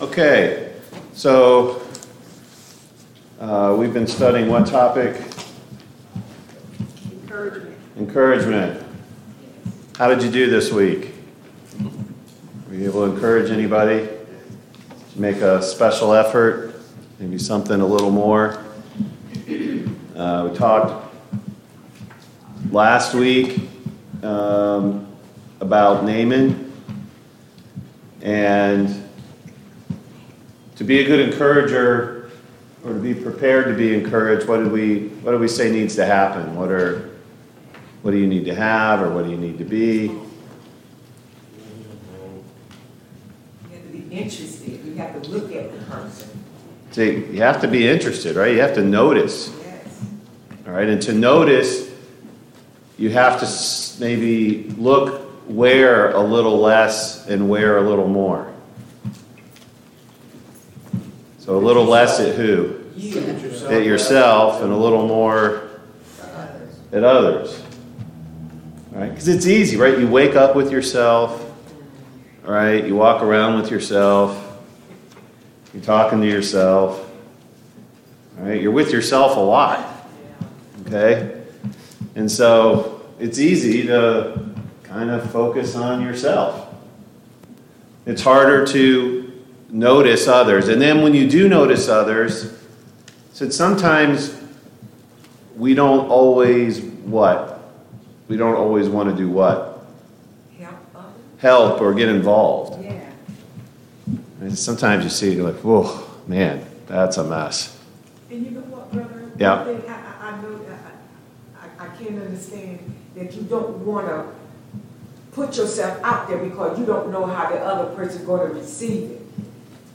Living outside of the Garden Passage: Exodus 3, Exodus 4, Exodus 14 Service Type: Sunday Morning Bible Class « 73.